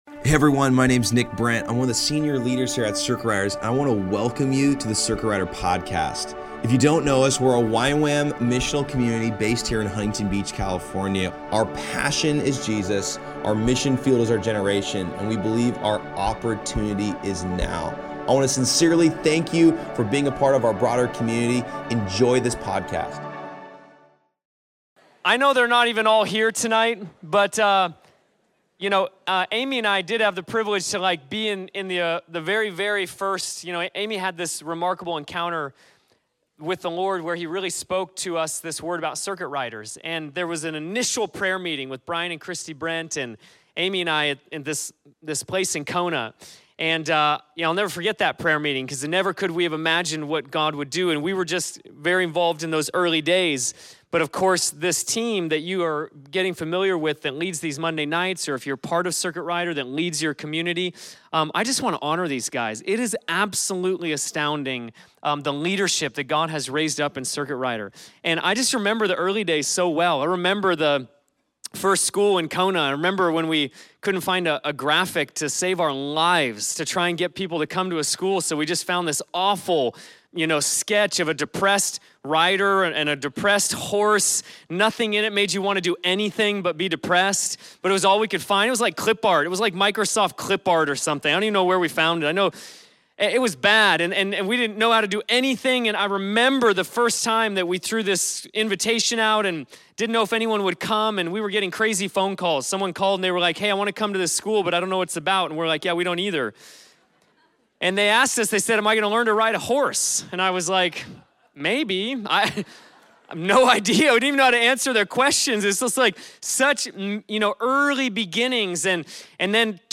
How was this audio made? He ends by praying for a fresh hunger for God to fall on everyone in the room.